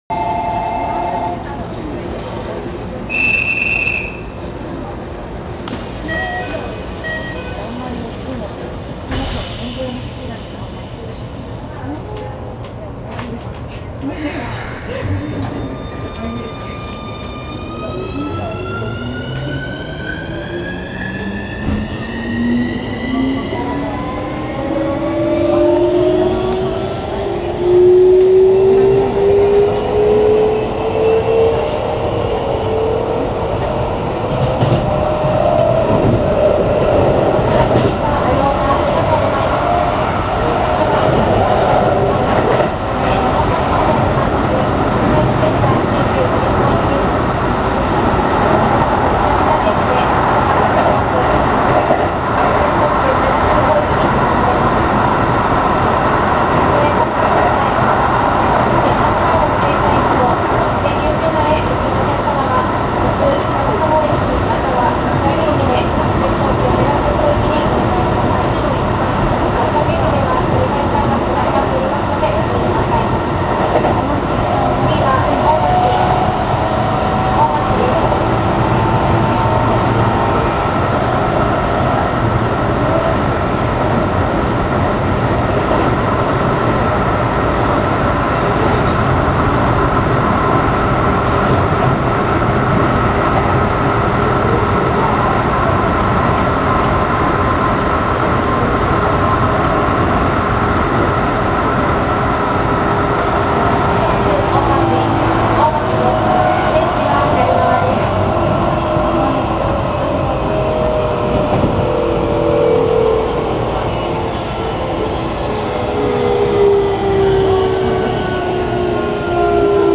・1000形(シーメンスIGBT)走行音
【北総線】新鎌ヶ谷〜大町（2分20秒：763KB）
ステンレス車を除いたIGBT車はシーメンスのIGBTを採用。音階は聞けなくなりました。他の車両では全く聞くことのできない音で、音量は相変わらず爆音のまま。